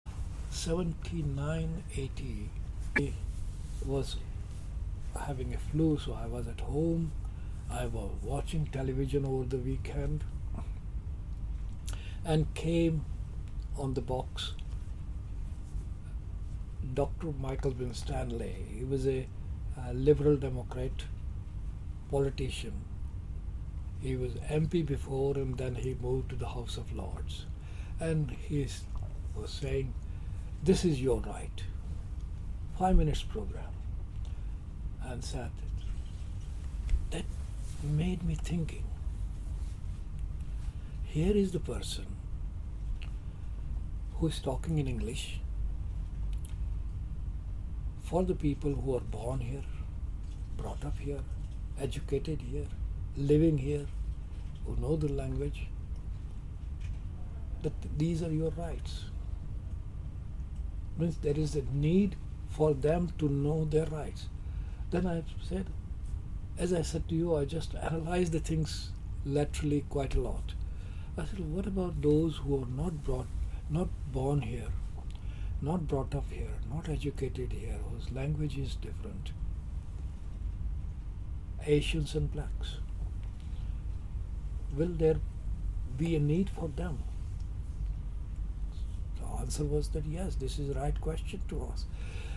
In this interview for the College in 2019, he talks about the impact the TV show had on his own practice.